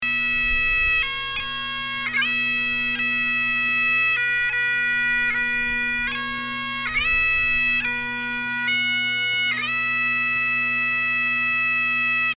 That was the Kansas Territorial Pipes and Drums performing for the large crowd gathered at the Kansas Veterans’ Cemetery to honor those who lost their lives in the line of duty.
0529-bagpipes.mp3